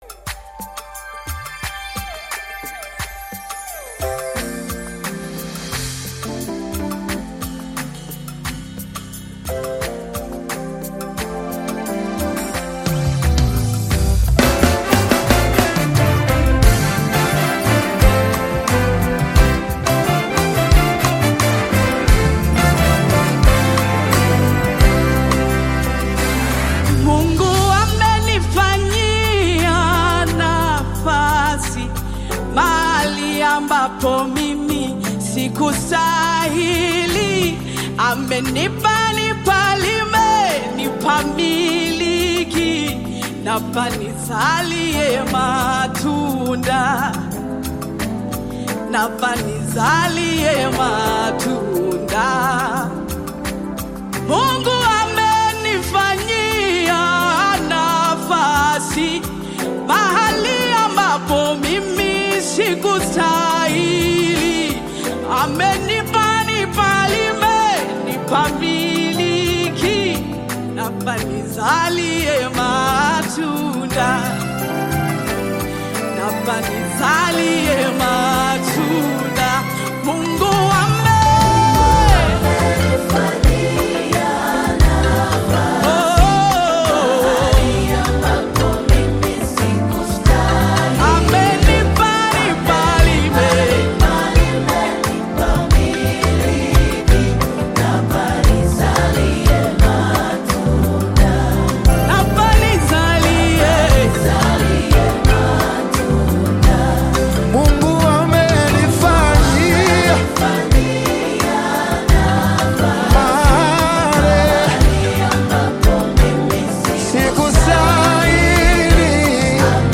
Gospel music track
Tanzanian gospel artists